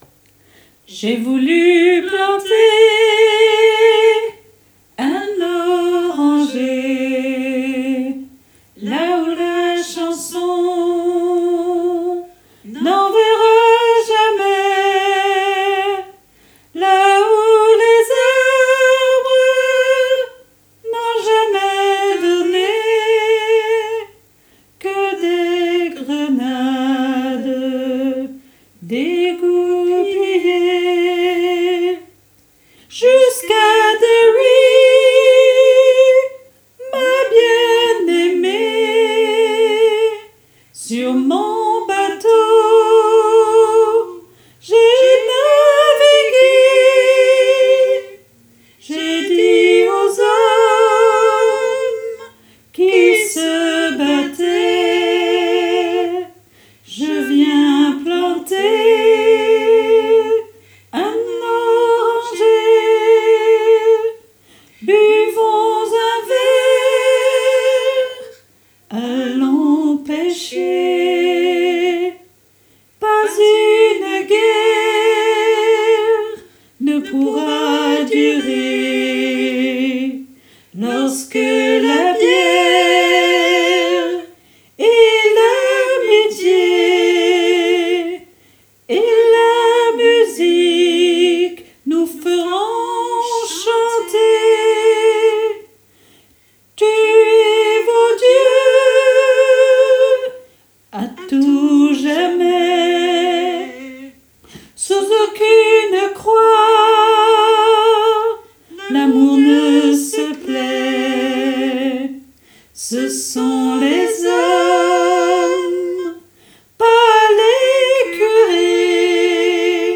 MP3 versions chantées
Hommes Et Autres Voix En Arriere Plan